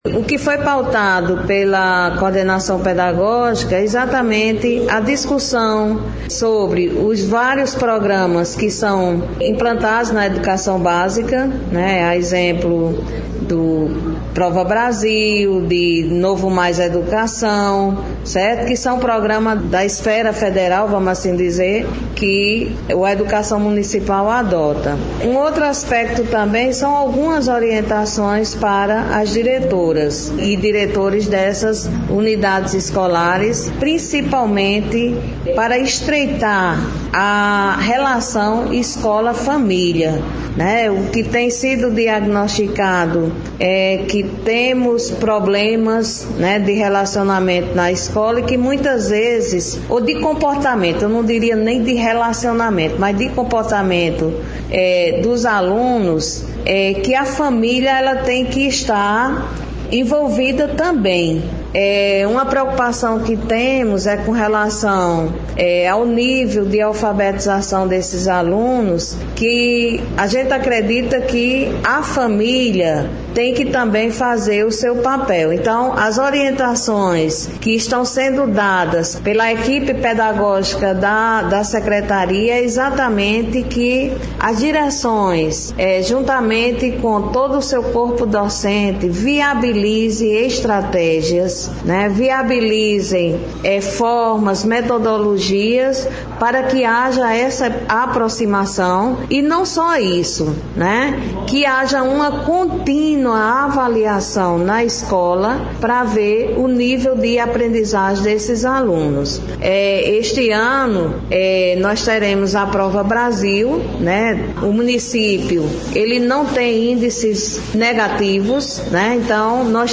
O evento aconteceu na sede da própria Secretaria de Educação, durante os turnos manhã e tarde, com intervalo para o almoço.
Secretária de Educação, Alana Candeia